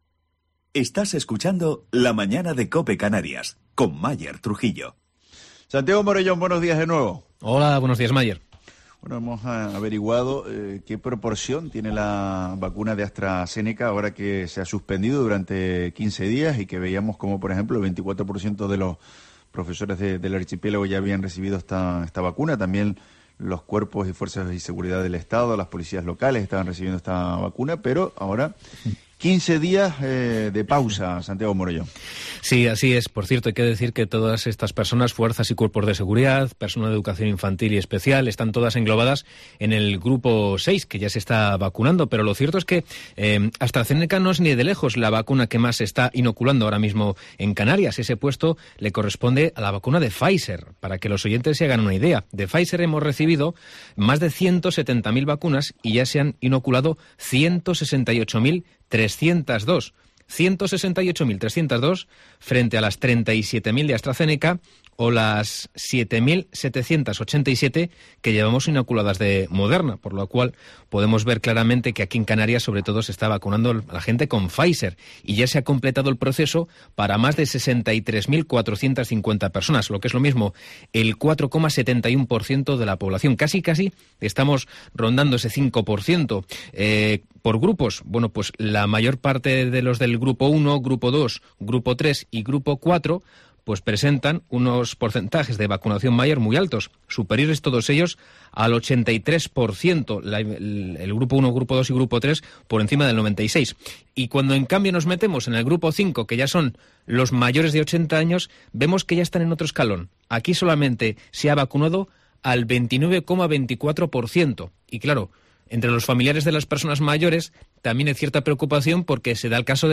Entrevista con familiares de personas mayores